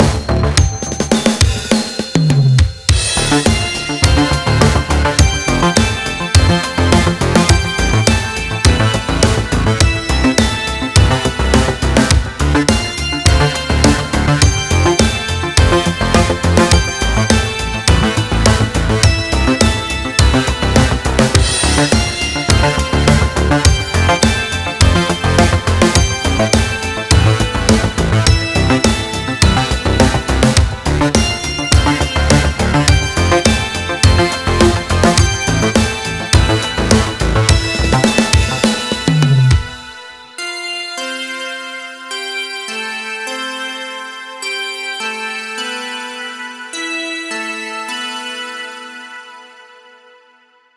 Пики выше 0 это же не нормально? А звучит вроде без искажений, как так? (видео)